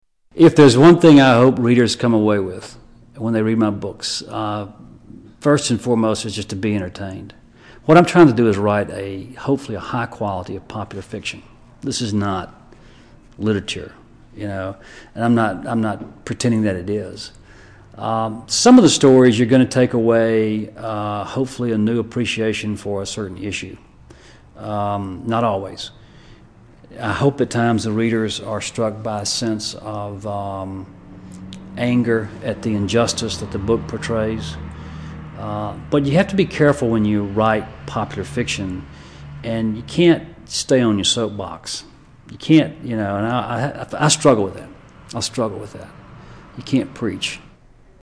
John Grisham Interview